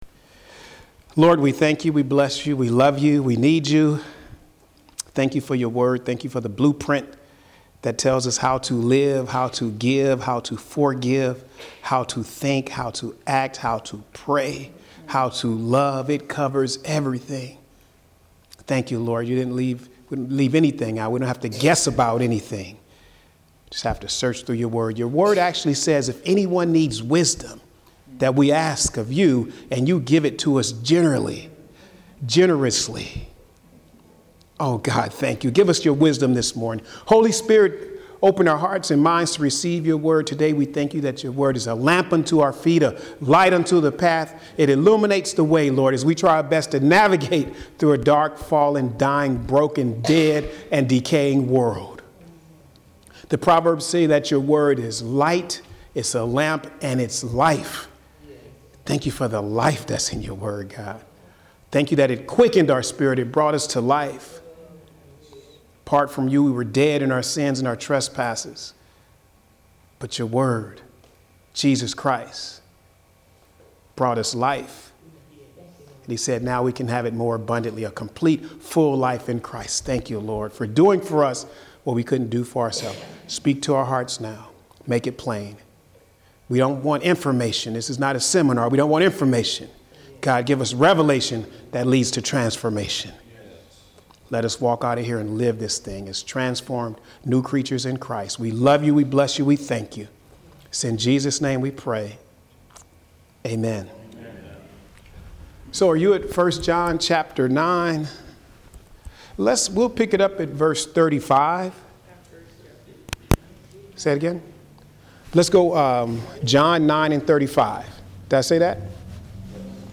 Home › Sermons › Jesus is The Light of The World Part 13